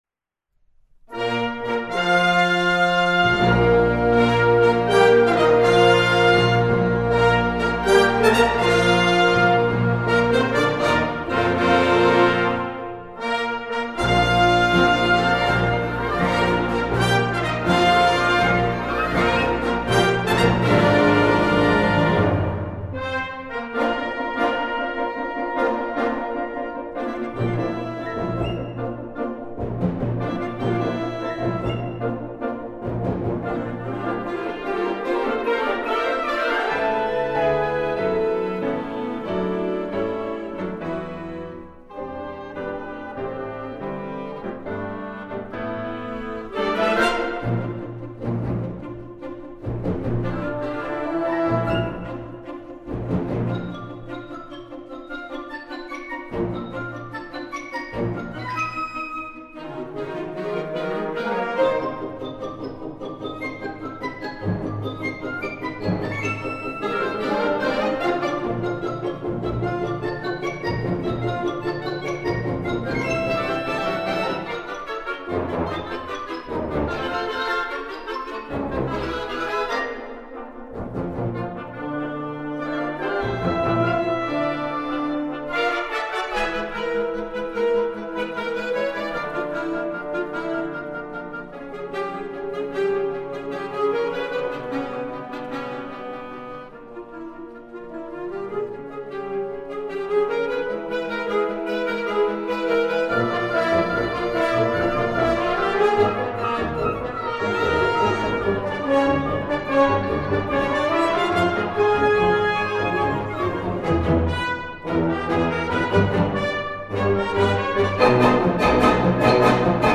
The colours of sound will change with the district.